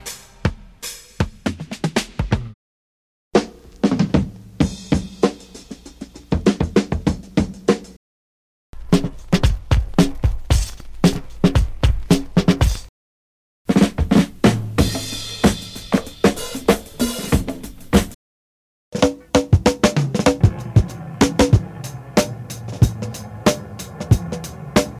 Вот аудио пример, там несколько фрагментов из старых песен: